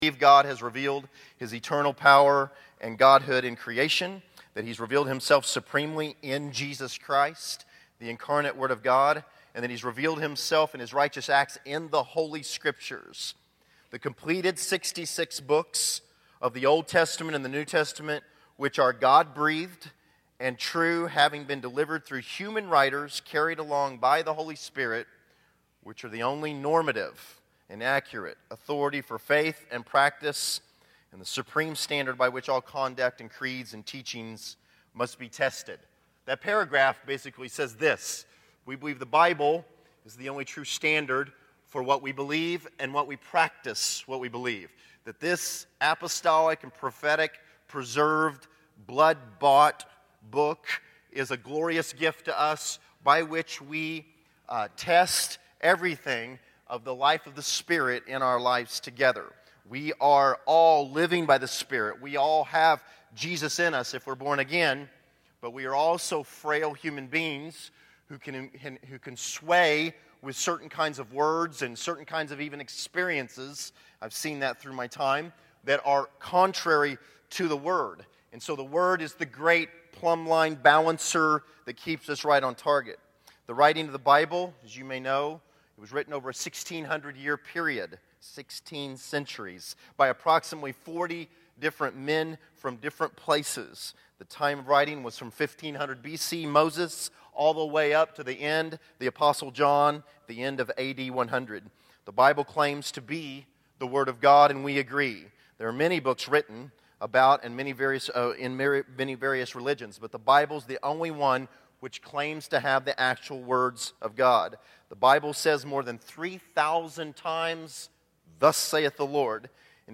Category: Scripture Teachings